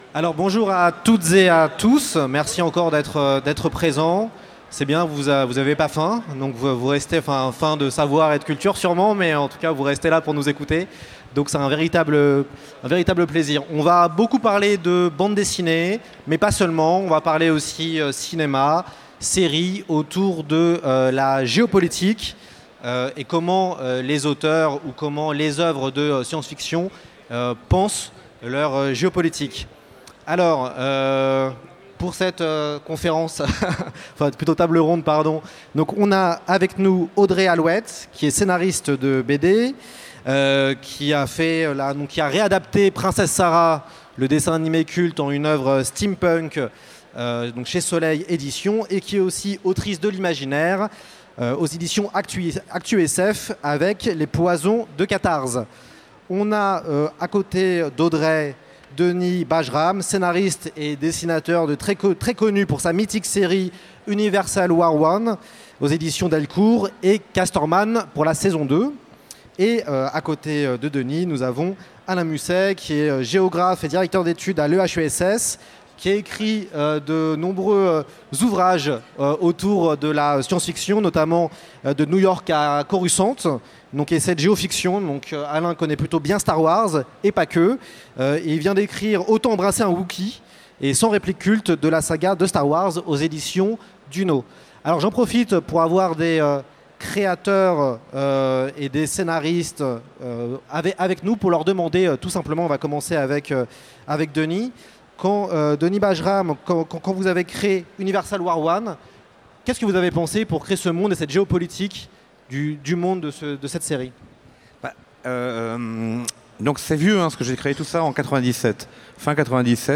Utopiales 2017 : Conférence Quelle géopolitique dans la bande dessinée d’anticipation